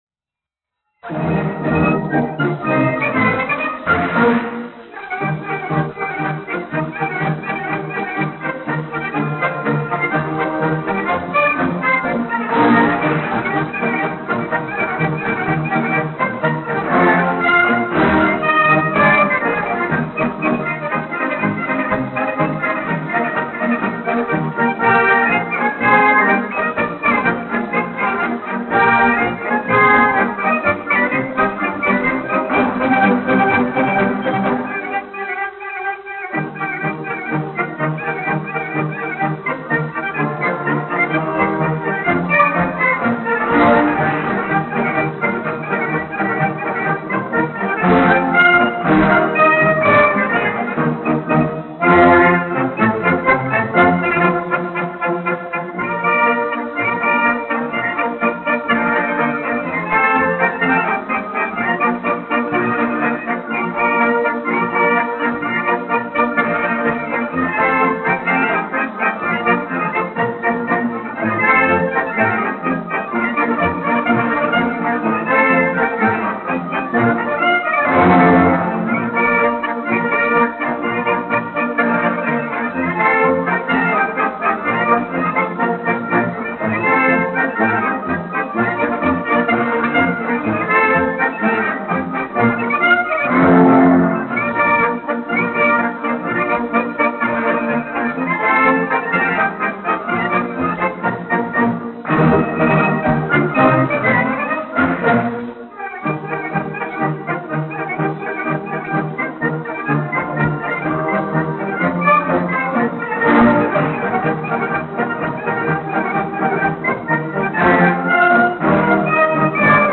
1 skpl. : analogs, 78 apgr/min, mono ; 25 cm
Pūtēju orķestra mūzika, aranžējumi
Marši
Skaņuplate